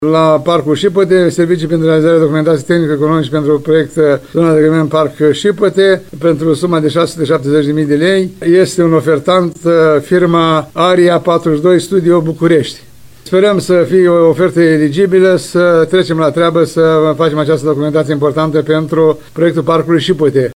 Primarul ION LUNGU a declarat că serviciile nu se vor limita la studiul de fezabilitate, ci constau și în elaborare studiilor de teren premergătoare.